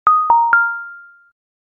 Wrong Sound Effect
Notification Sounds / Sound Effects
Alert-sms-tone.mp3